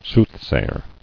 [sooth·say·er]